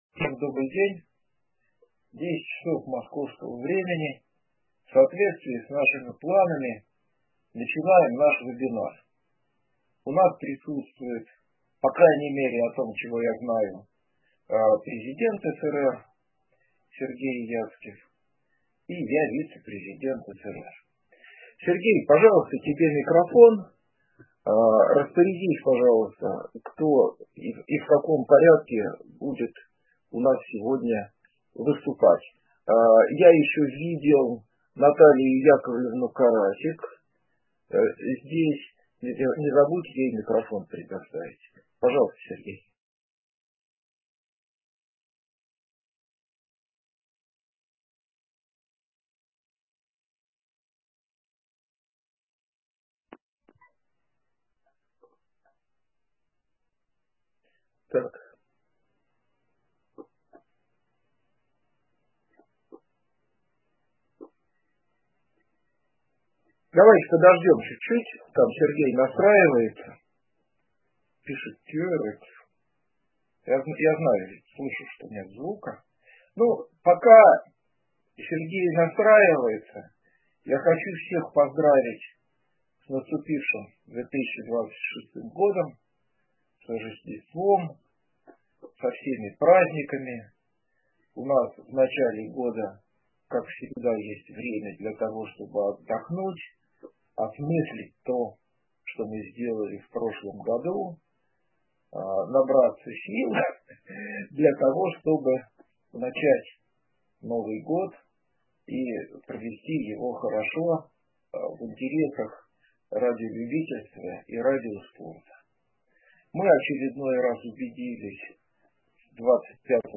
Новогодний вебинар состоялся